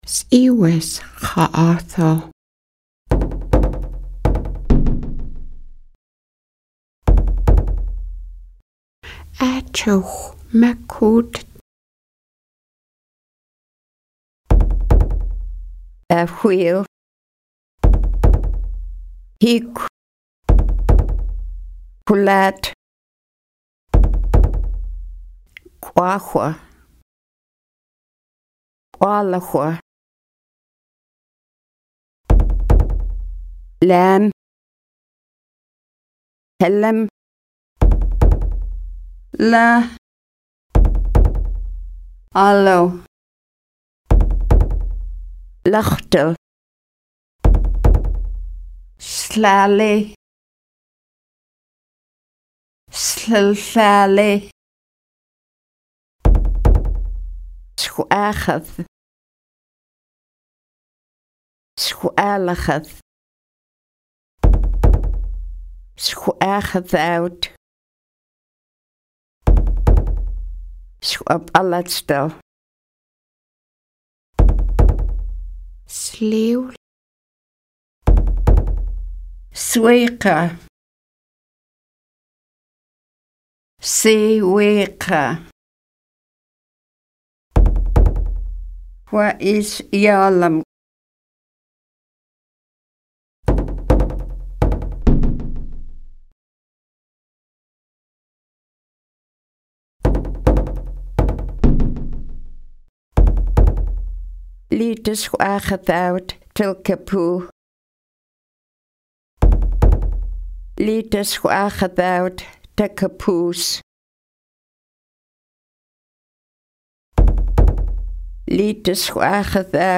Listen to the elder
Full Lesson Audio S4- Vocabulary, phrases, and Dialogues